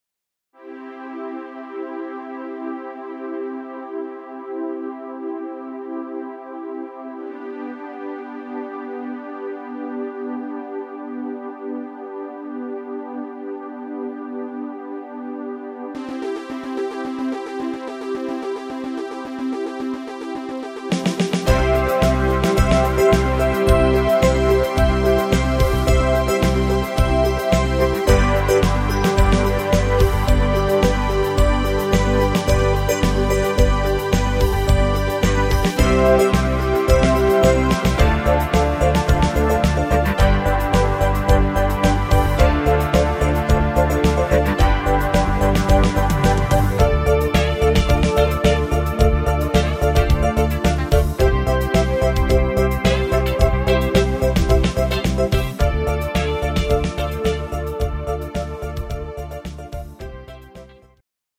Rhythmus  80-er Disco
Art  Pop, Englisch, Oldies